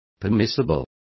Complete with pronunciation of the translation of permissible.